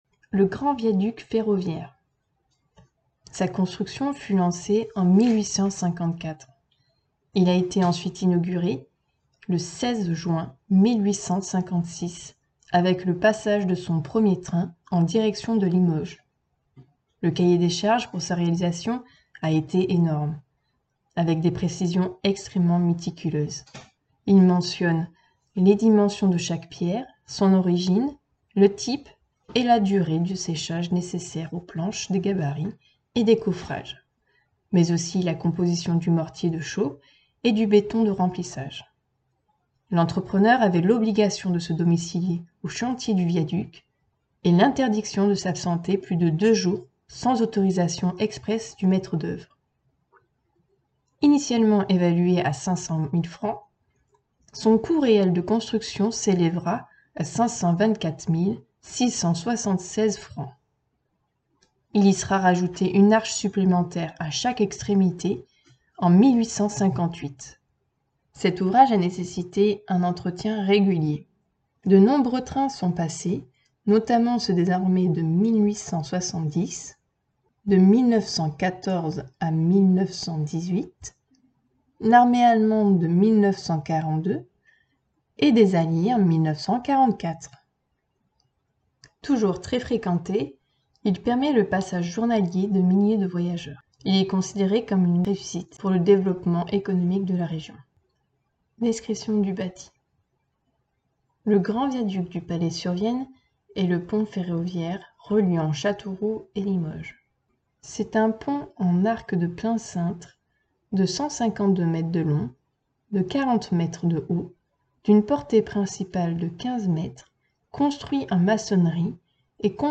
Lecture audio française du panneau ici.